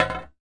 滴酸
描述：这是一种酸性合成物的下降
Tag: 140 bpm Acid Loops Fx Loops 1.15 MB wav Key : Unknown